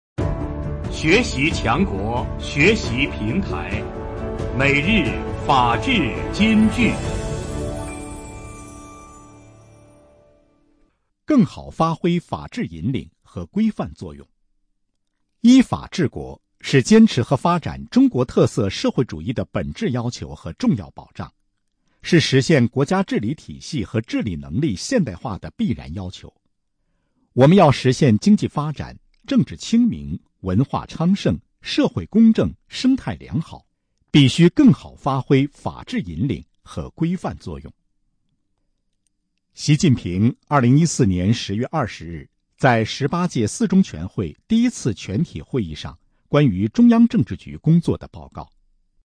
每日法治金句（朗读版）|更好发挥法治引领和规范作用 _ 学习宣传 _ 福建省民政厅